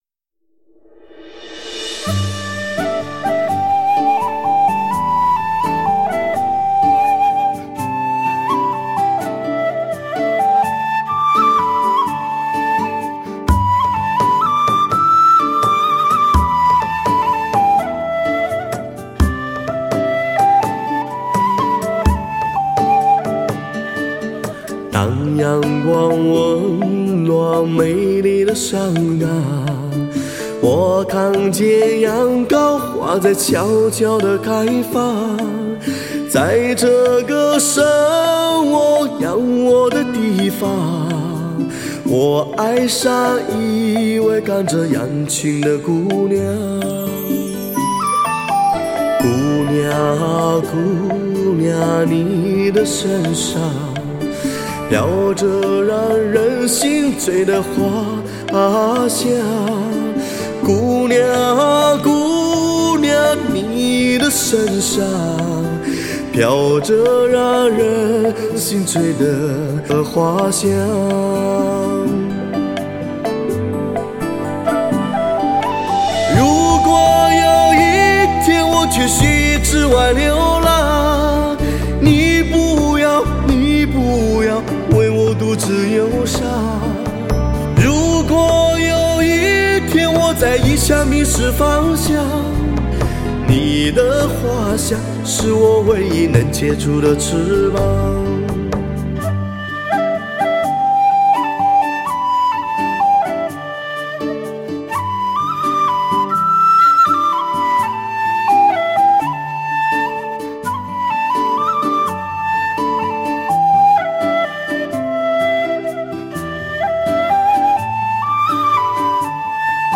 西双版纳少数民族的山寨欢歌，圣洁雪域高原无尽的传说与向往，